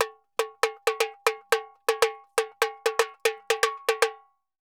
Tamborin Candombe 120_2.wav